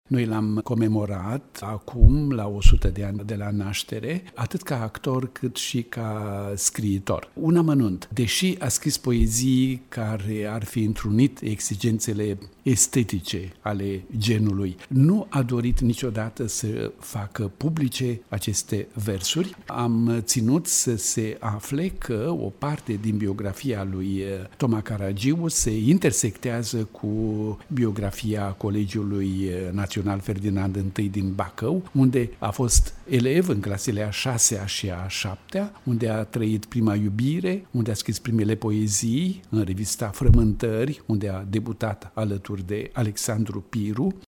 La Colegiul Național Ferdinand din Bacău a avut loc, ieri, o scurtă evocare a marelui actor Toma Caragiu. Evenimentul face parte din proiectul aniversar „Toma Caragiu – 100 de ani”, desfășurat pe parcursul acestui an, și reprezintă un omagiu adus uneia dintre cele mai marcante figuri ale teatrului și cinematografiei românești.